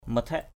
/mə-tʱɛʔ/ (t.) có dáng = manières, aspect = figure, externality. ngap mathaik ZP m=EK làm dáng = se faire beau = coquetry.
mathaik.mp3